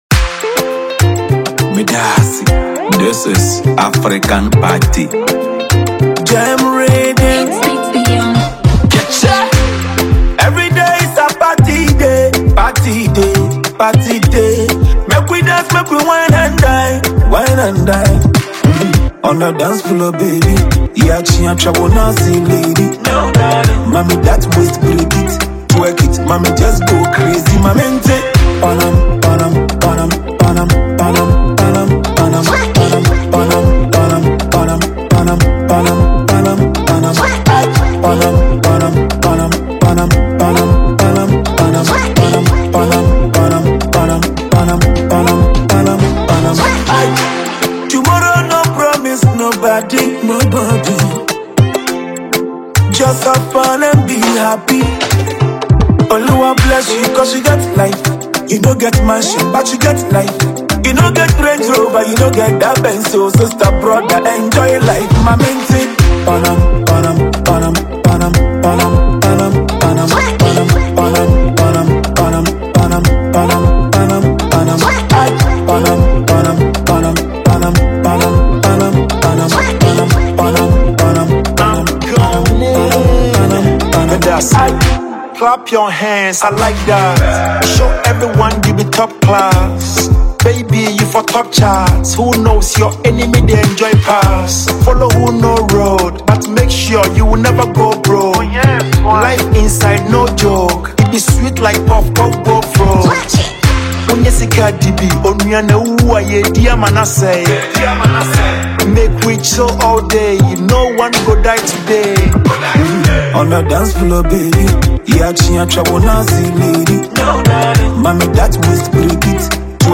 Known for blending Afropop, highlife, and dance-ready vibes